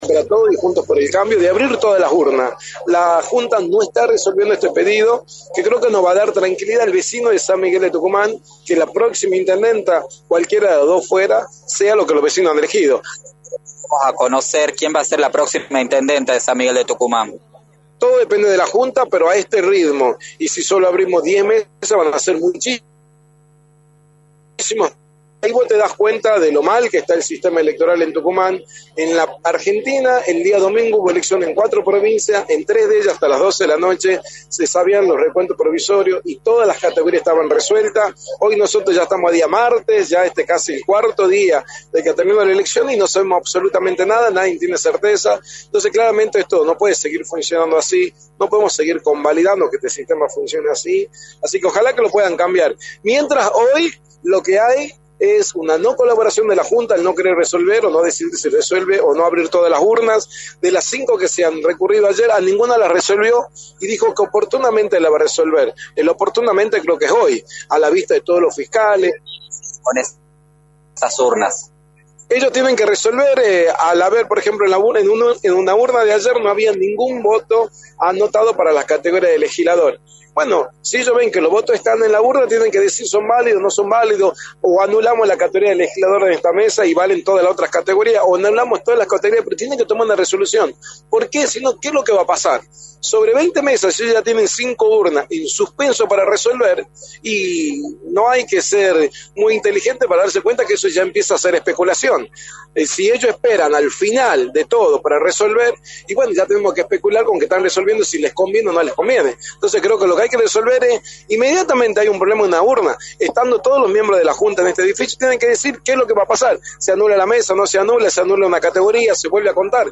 Freddy Toscano, Secretario de Obras de la Municipalidad de San Miguel de Tucuman y Legislador electo por Juntos por el Cambio, aseguró en Radio del Plata Tucumán, por la 93.9, que existen irregularidades al momento de realizar el escrutinio definitivo.
“La Junta no está resolviendo el pedido de abrir todas la urnas, el cual va a dar tranquilidad al vecino de San Miguel de Tucumán de que la próxima Intendenta sea la que los vecinos han elegido” remarcó Freddy Toscano en entrevista para Radio del Plata Tucumán, por la 93.9.